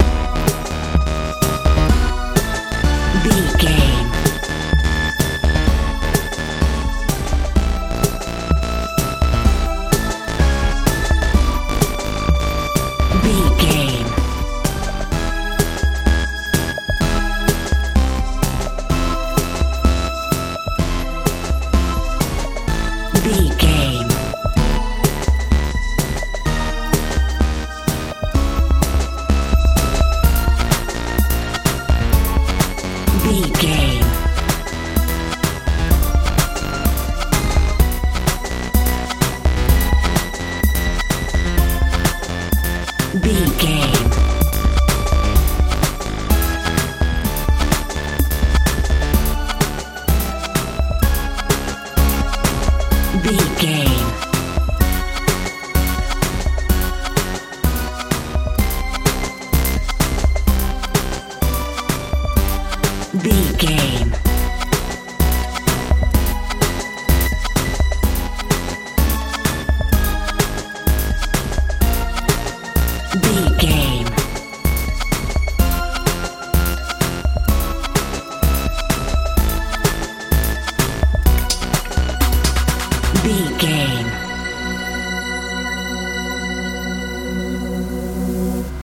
dance pop
Ionian/Major
A♭
confused
dramatic
synthesiser
bass guitar
drums
80s
tension
suspense